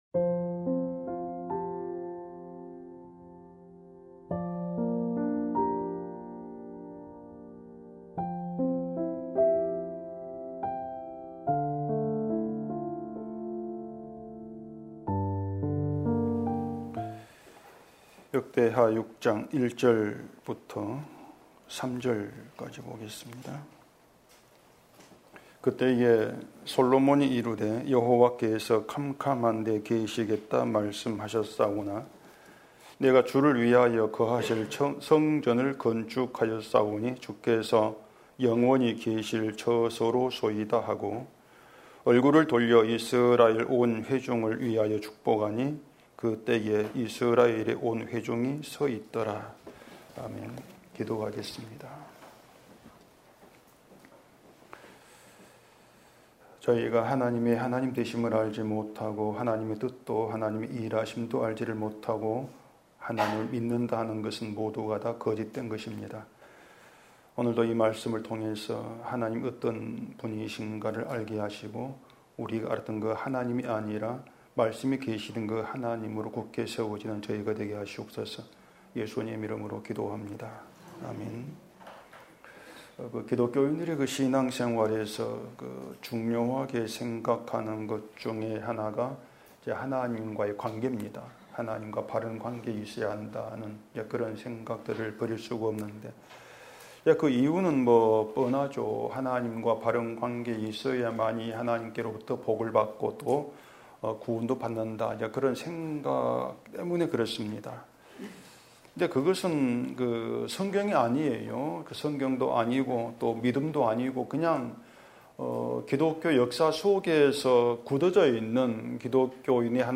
주일오전 - (19강) 어둠 속의 여호와